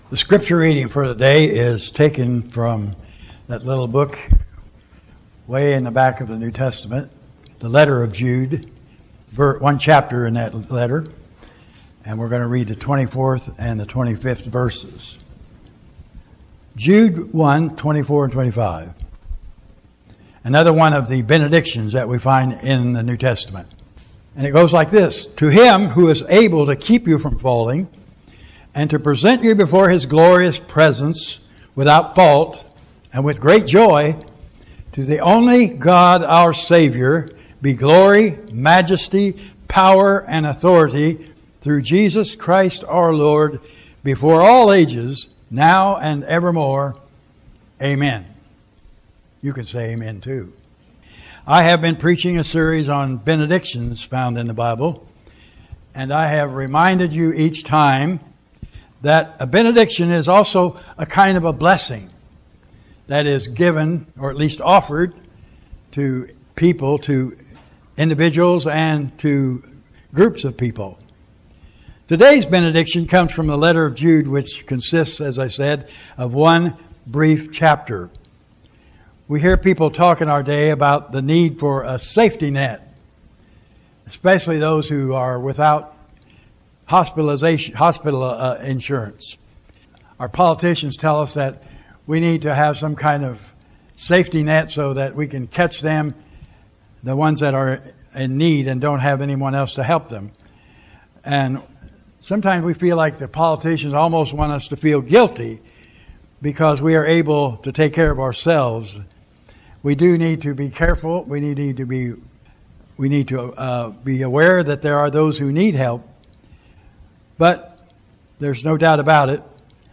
Jonah 4 Audio sermons